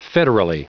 Prononciation du mot federally en anglais (fichier audio)
Prononciation du mot : federally